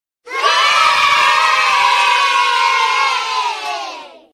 YAAAAAAAAY Meme Sound Effect sound effects free download